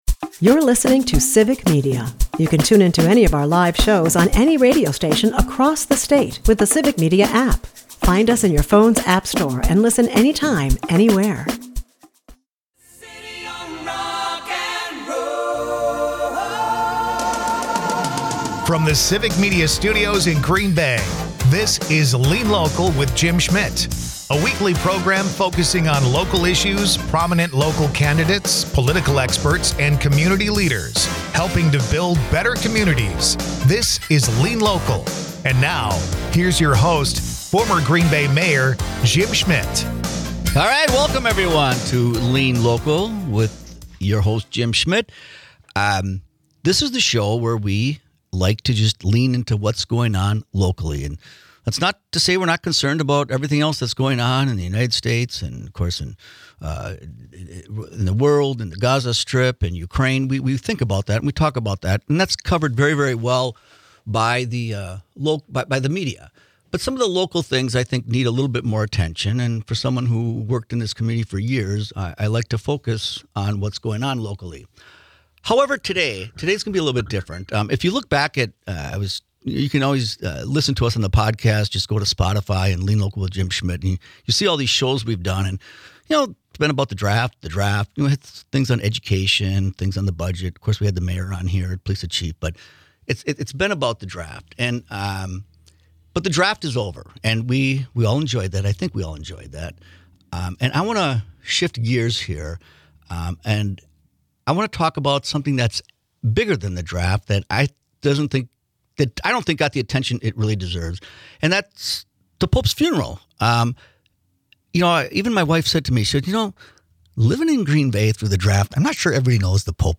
Lean Local is a part of the Civic Media radio network and airs Sunday's from 1-2 PM on WGBW .
Dive into the heart of community issues with 'Lean Local,' hosted by former Green Bay Mayor Jim Schmitt.